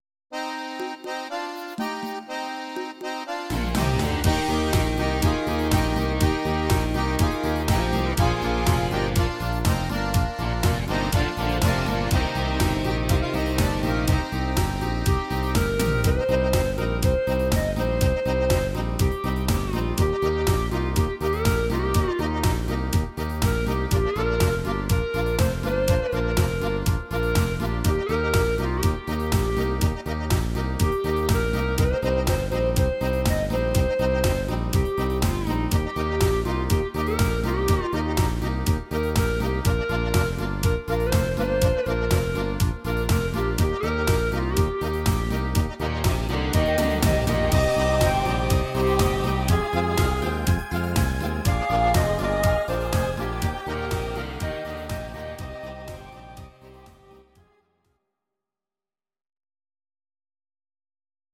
Audio Recordings based on Midi-files
Pop, 1980s